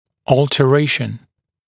[ˌɔːltə’reɪʃn][ˌо:лтэ’рэйшн]изменение, перемена, альтерация